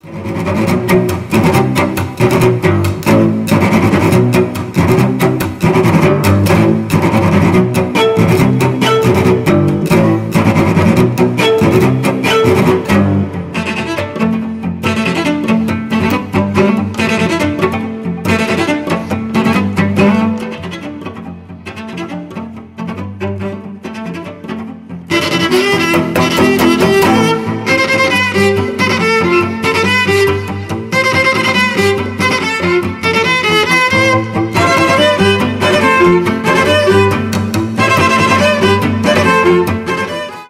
виолончель , инструментал , cover